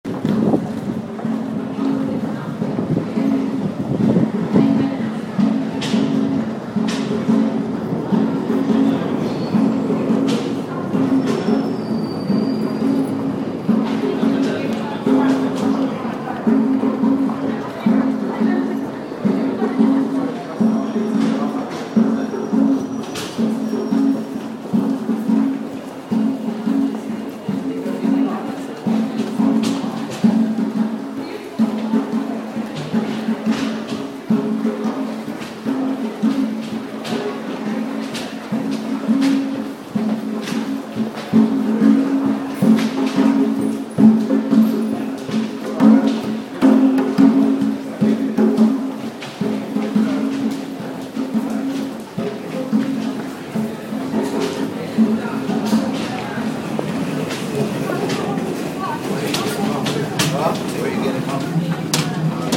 Big drum at bank